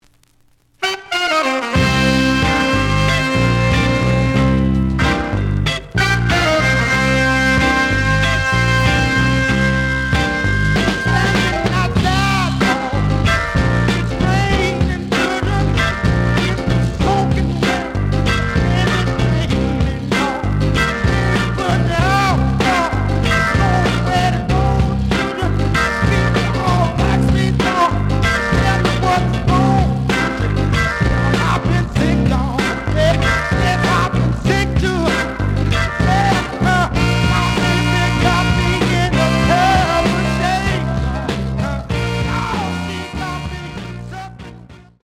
The audio sample is recorded from the actual item.
●Genre: Soul, 60's Soul
Slight noise on both sides.)